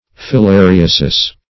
Filariasis \Fil`a*ri"a*sis\, n. [NL.]